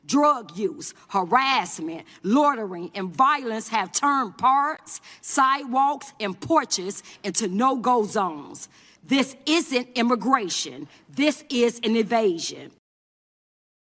During a House hearing in April